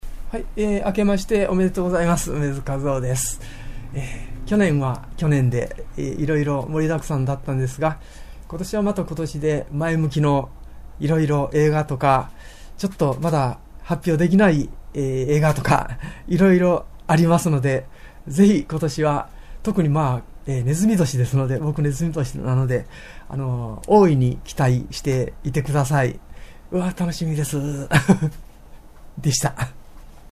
楳図かずおから新年のメッセージ